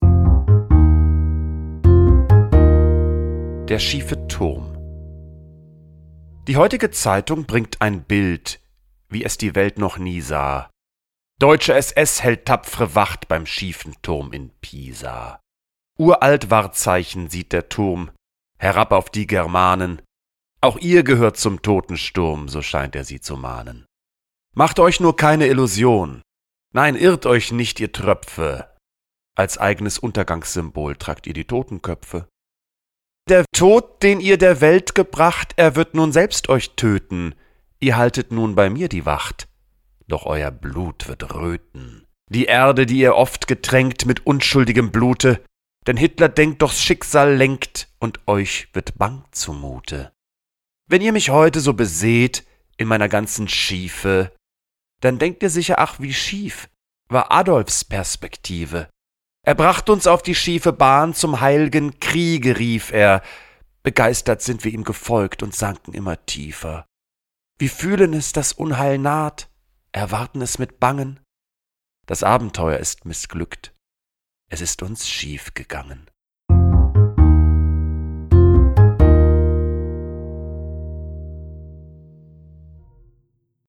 Jan-Boehmermann__TURM_mit-Musik.m4a